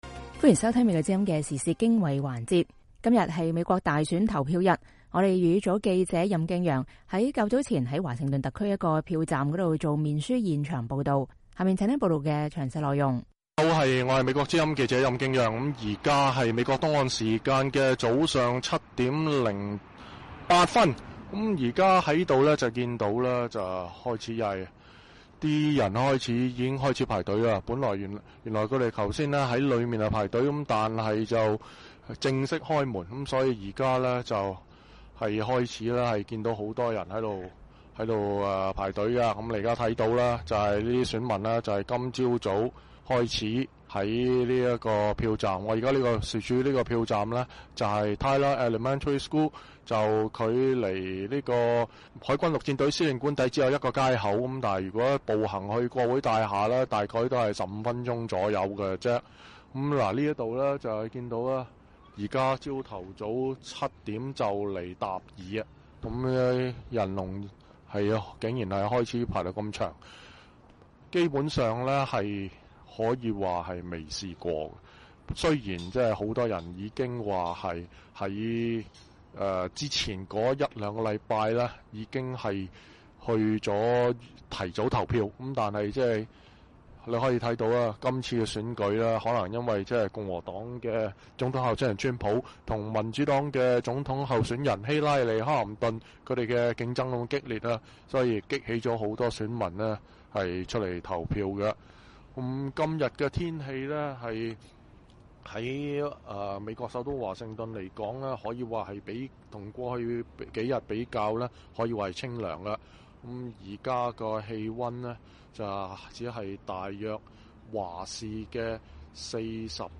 美國之音粵語組在DC投票站用面書現場報導